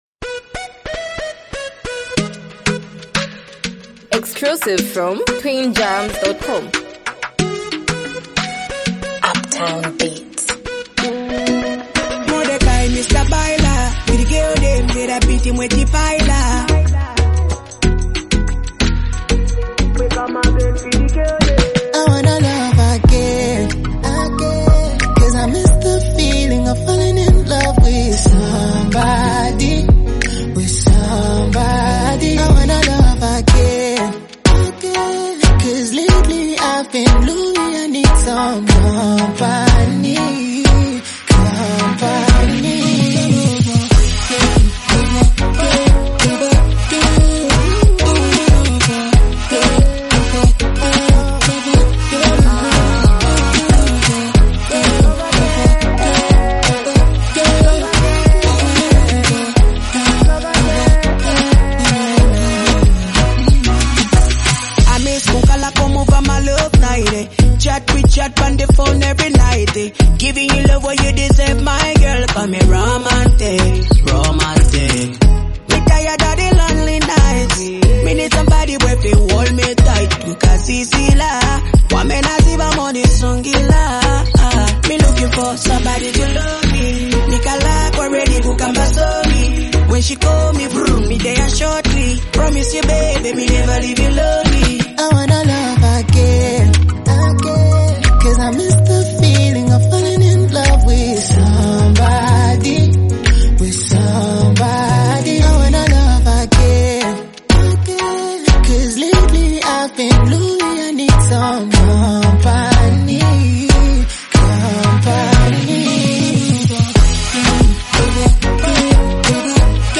delivers his verses with honesty and vulnerability
smooth vocals and emotional expression